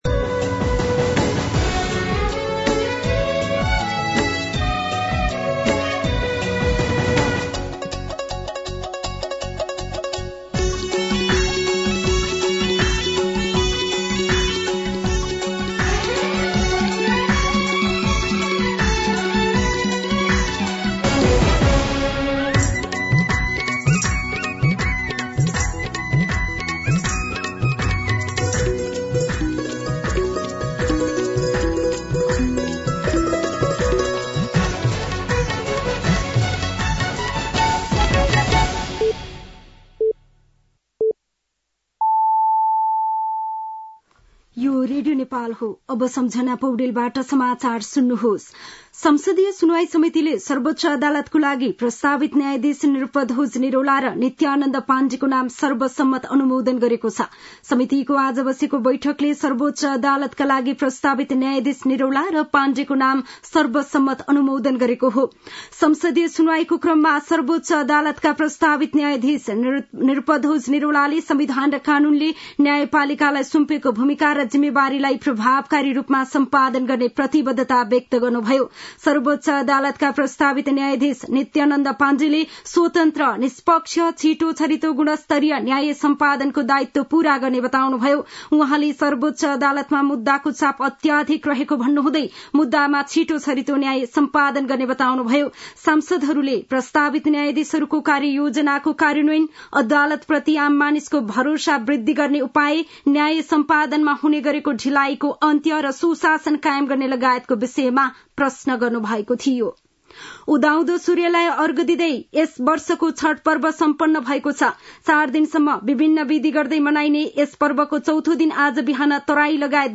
दिउँसो ४ बजेको नेपाली समाचार : २४ कार्तिक , २०८१
4-pm-News-.mp3